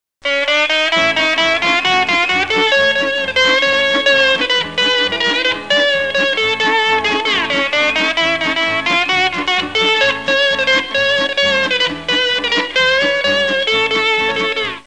tigers1.mp3